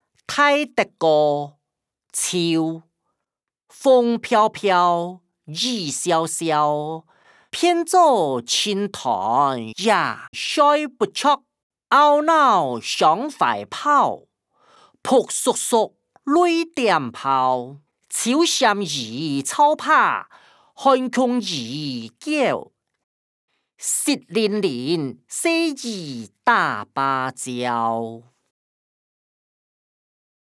詞、曲-大德歌‧秋音檔(大埔腔)